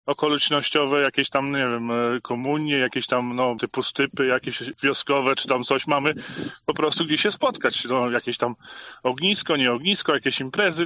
Sołtys Rostkowski dodaje, że miejsce będzie dobrze wykorzystane: